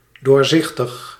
Ääntäminen
IPA: /ˈtɕistɨj/